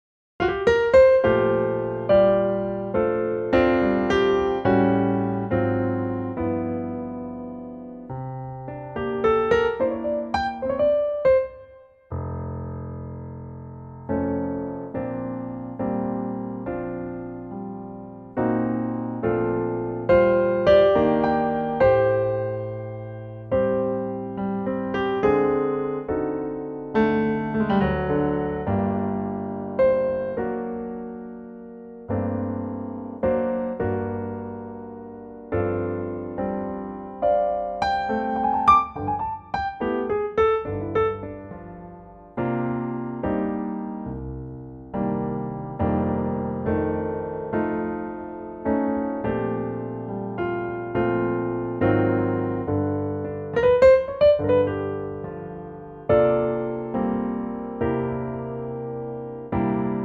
key Bb 3:24
key - Bb - vocal range - C to Eb
in a lovely piano only arrangement.